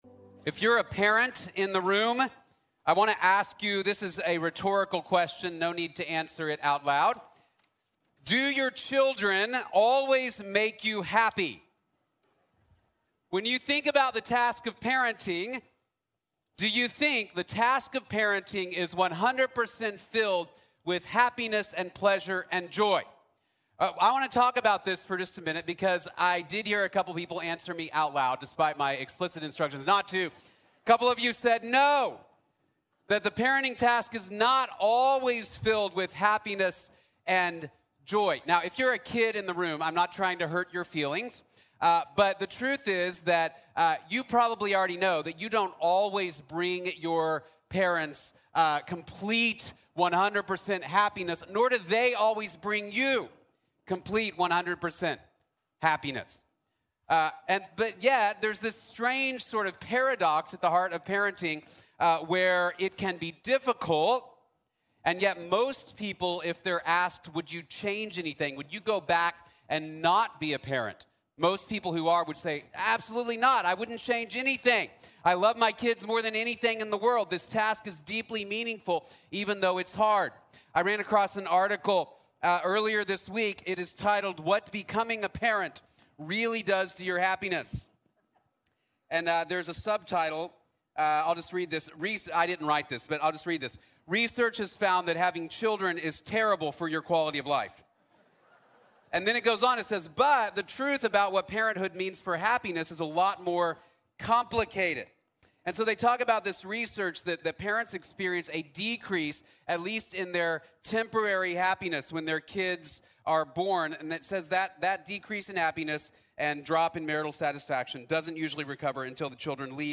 Jesús vale la pena la lucha | Sermón | Iglesia Bíblica de la Gracia